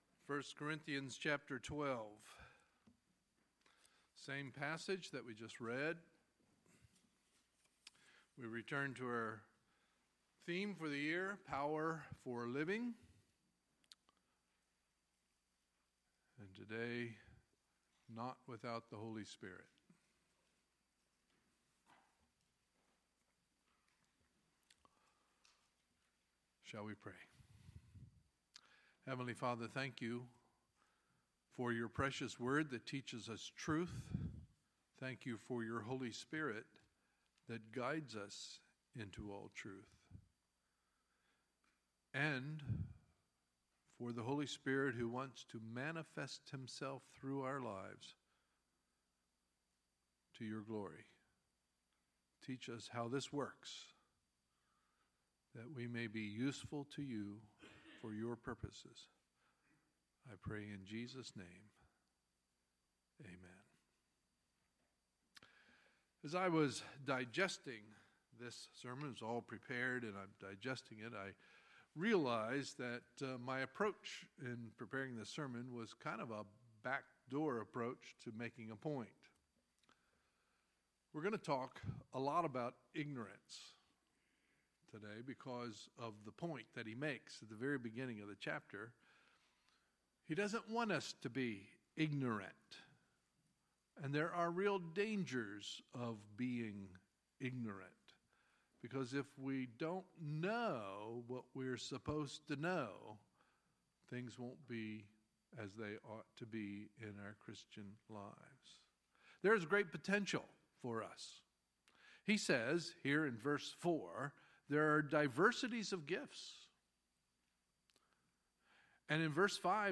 Sunday, May 28, 2017 – Sunday Morning Service